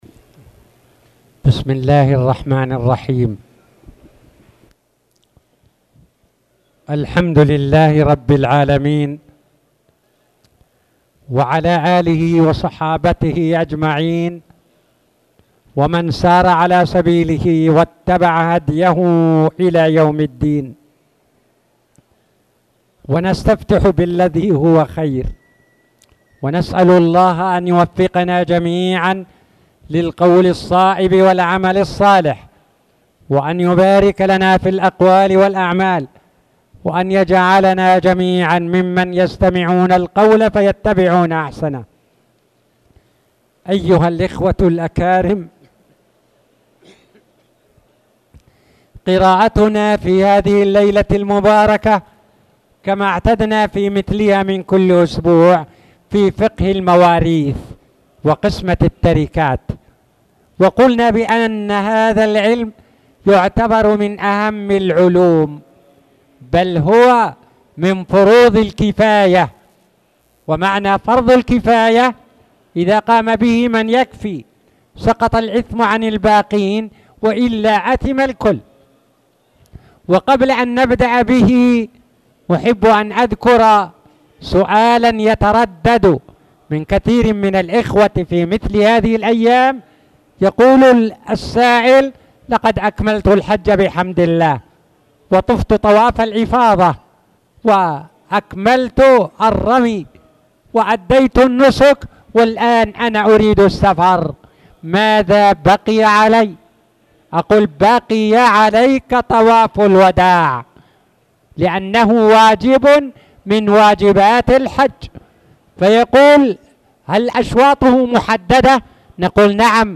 تاريخ النشر ٢٤ ذو الحجة ١٤٣٧ هـ المكان: المسجد الحرام الشيخ